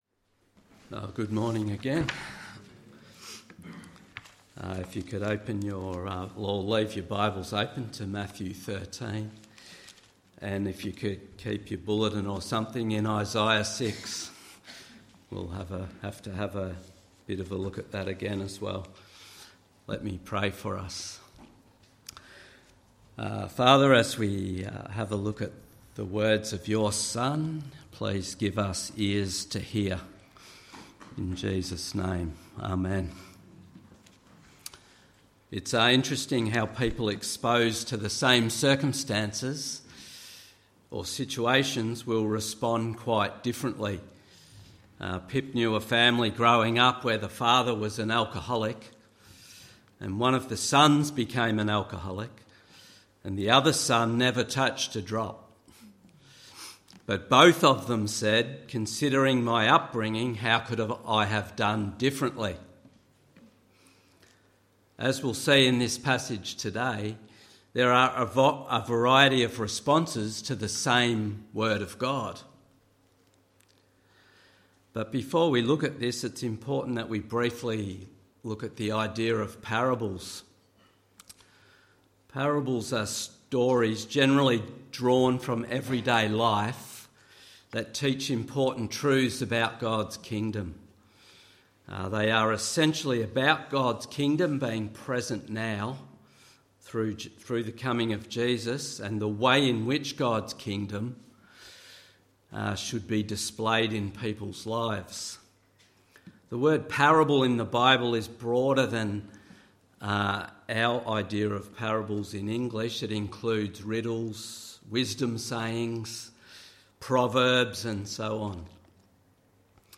Sermon: Matthew 13:24-53